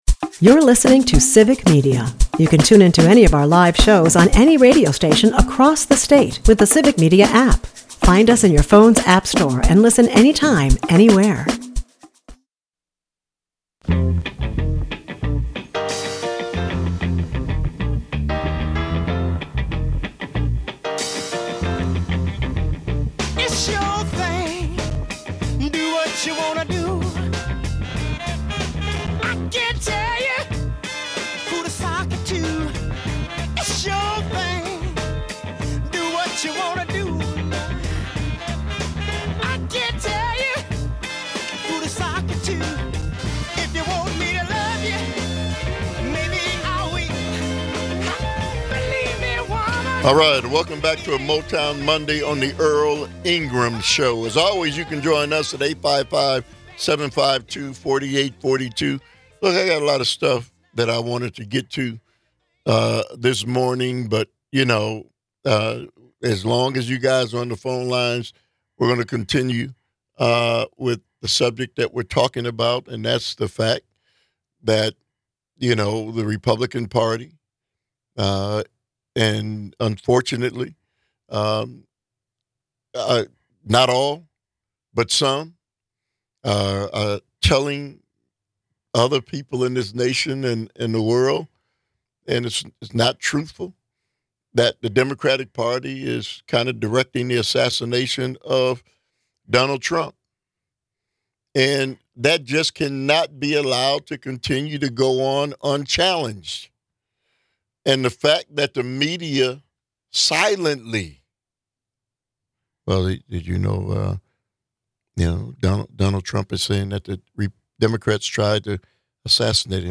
At the end of the show, he is joined by Kentucky Governor Andy Beshear to discuss Kamala Harris's plan for rural America.